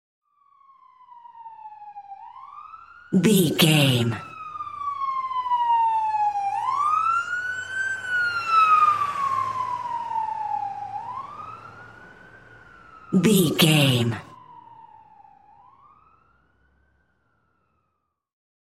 Ambulance Ext Passby Large Siren
Sound Effects
urban
chaotic
emergency